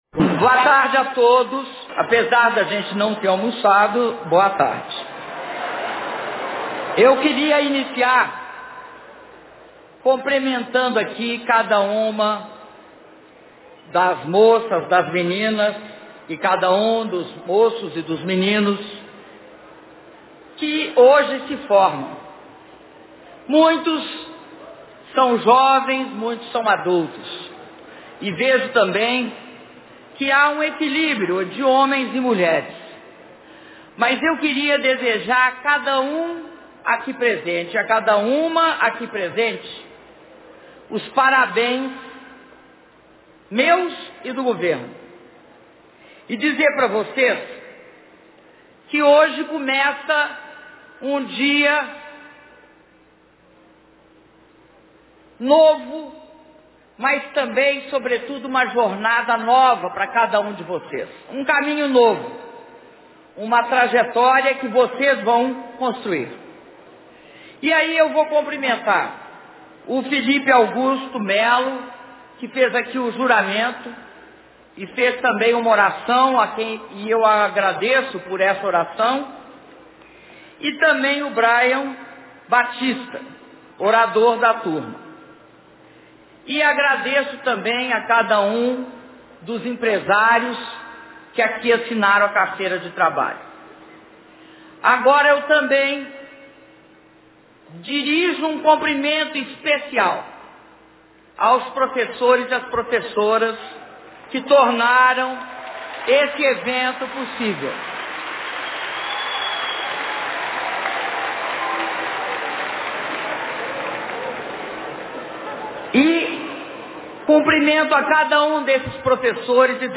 Áudio do discurso da Presidenta da República, Dilma Rousseff, na cerimônia de formatura de alunos do Pronatec - Belo Horizonte/MG (23min46s)